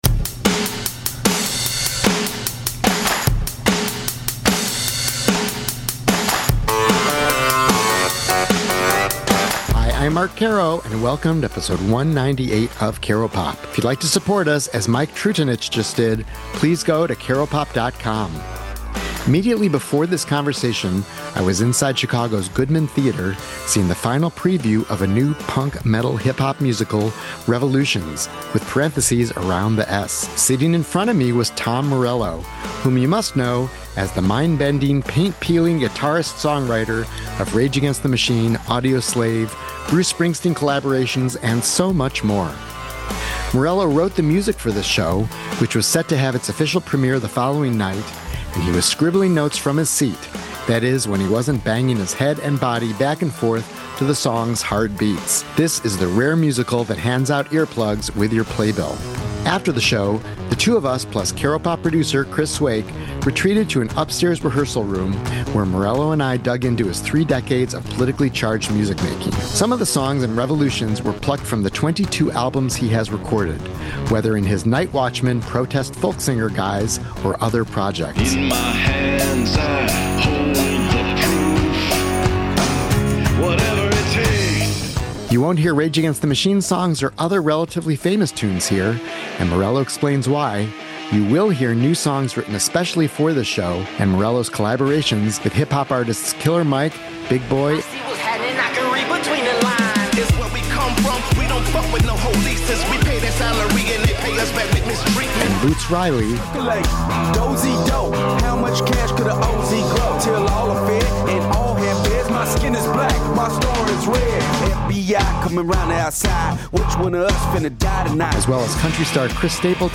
This conversation with Rage Against the Machine/Audioslave guitarist-songwriter Tom Morello took place immediately after the final preview of the new punk-metal-hip-hop musical, Revolution(s) at Chicago’s Goodman Theatre.